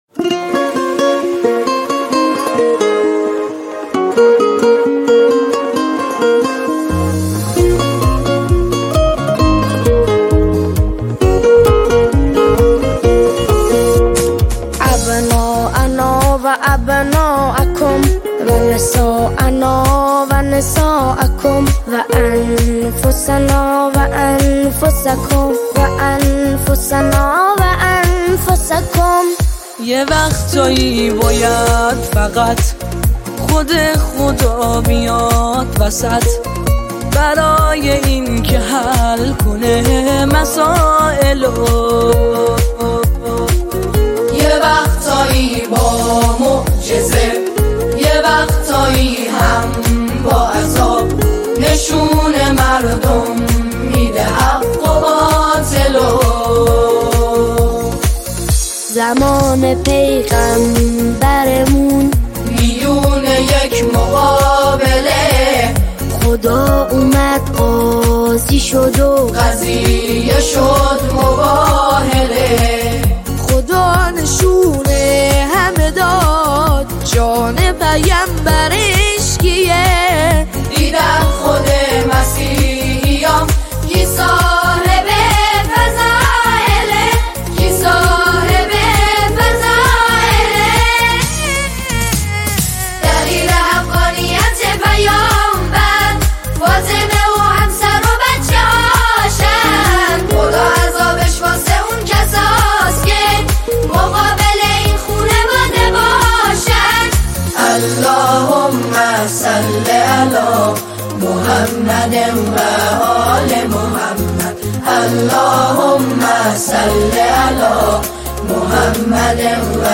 سرودی برای روز اثبات حقانیت